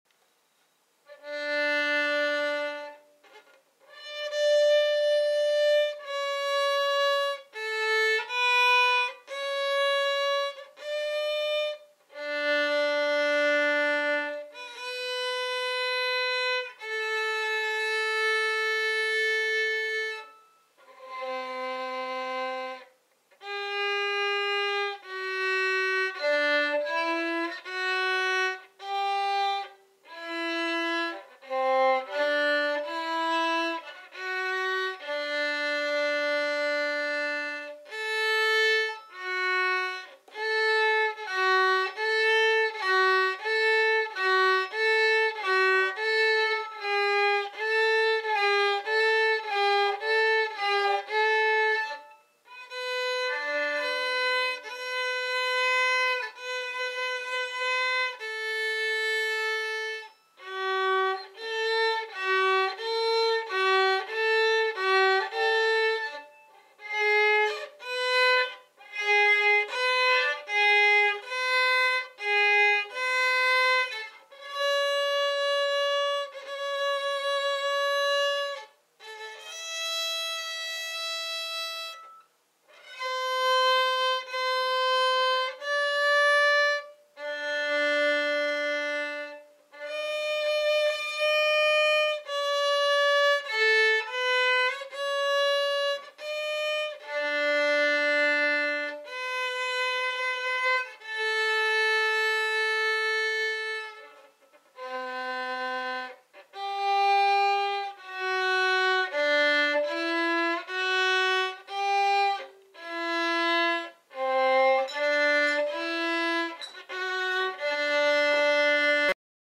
I practiced this song yesterday Dec 2, 2019.